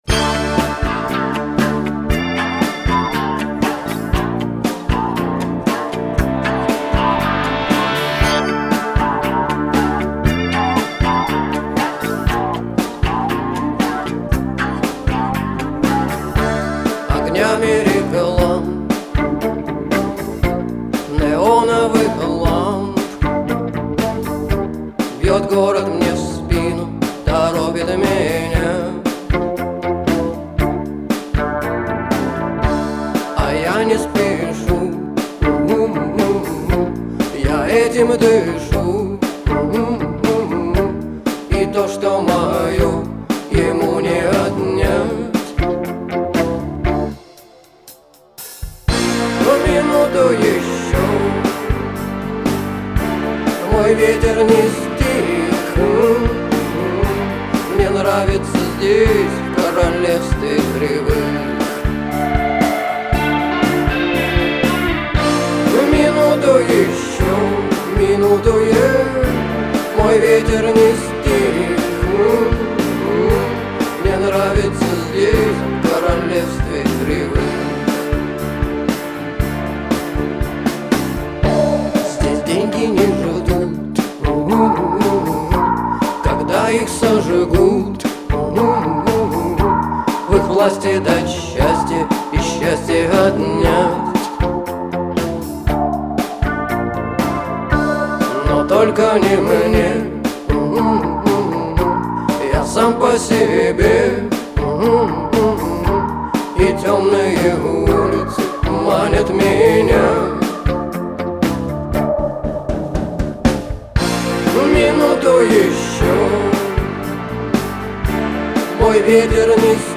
Русский Рок